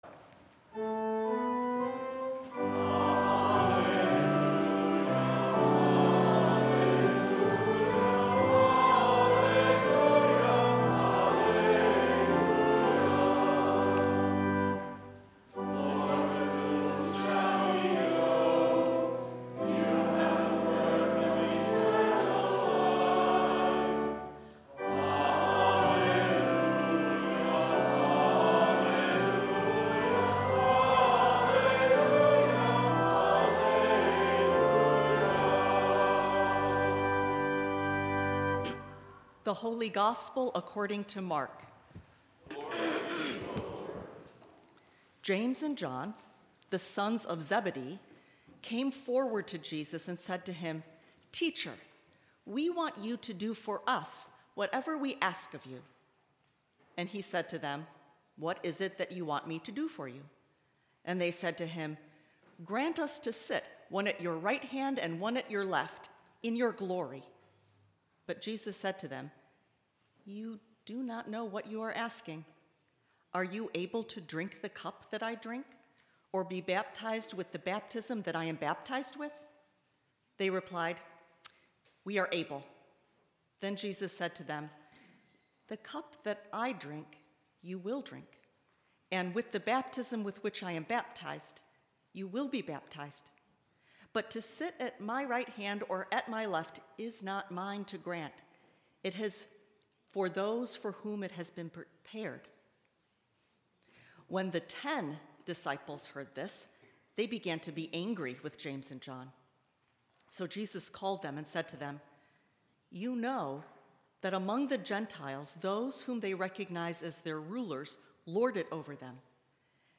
Sermon 22nd Sunday after Pentecost